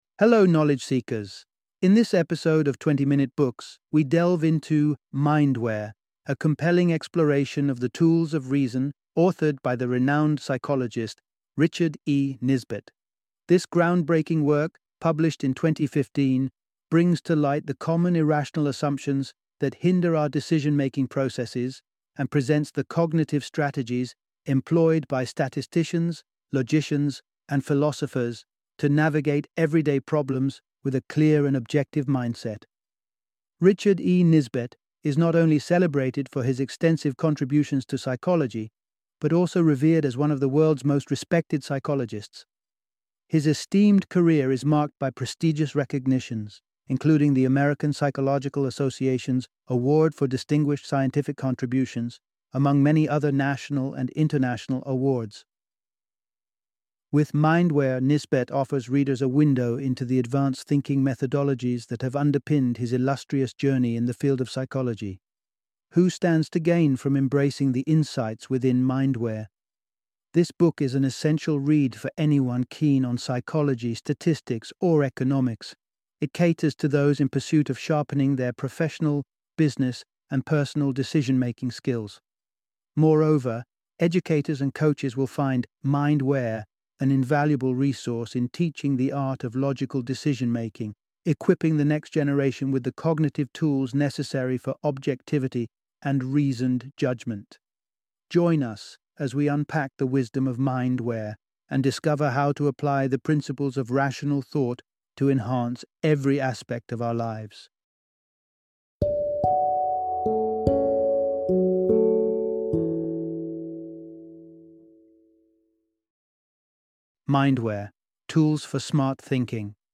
Mindware - Audiobook Summary